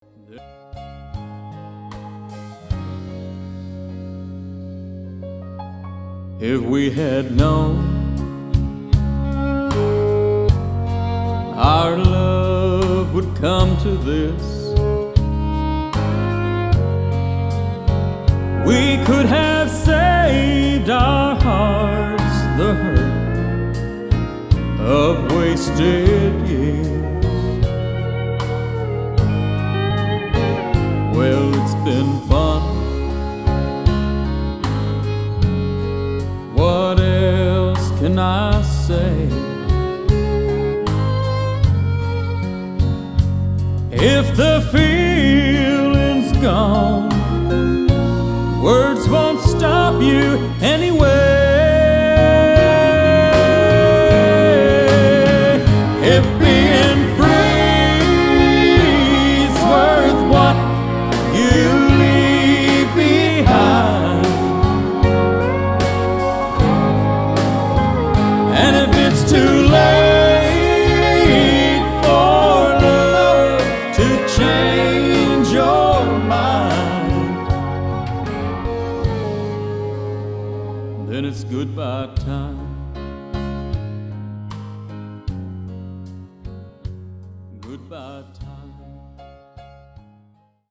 11 Country Songs